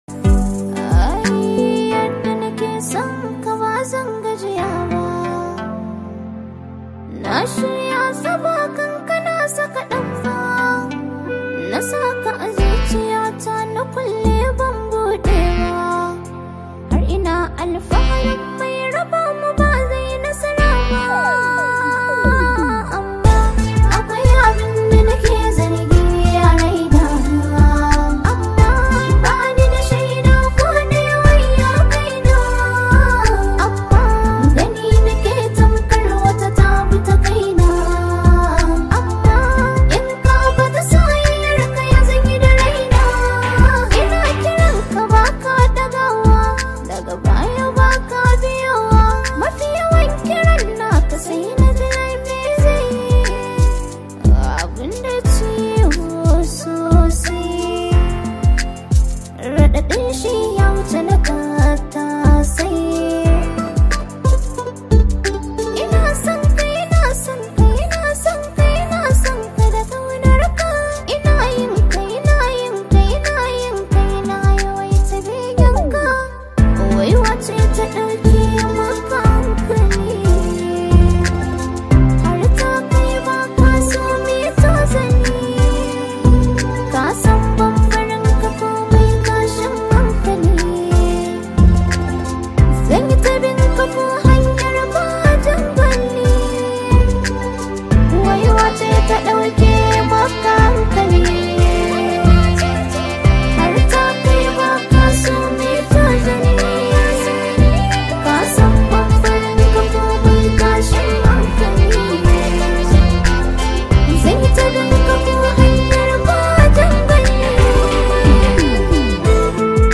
Hausa Songs
waƙoƙi masu ma’ana da kuma sanyin murya